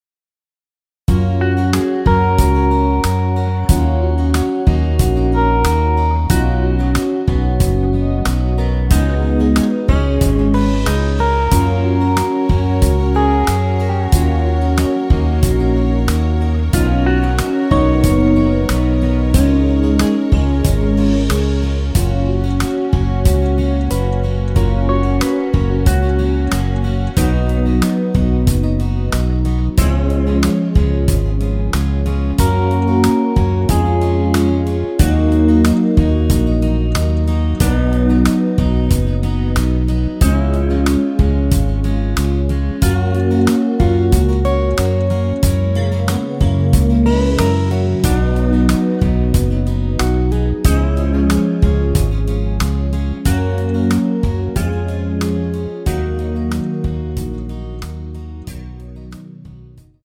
원키에서(-1)내린 MR입니다.
F#
◈ 곡명 옆 (-1)은 반음 내림, (+1)은 반음 올림 입니다.
앞부분30초, 뒷부분30초씩 편집해서 올려 드리고 있습니다.
중간에 음이 끈어지고 다시 나오는 이유는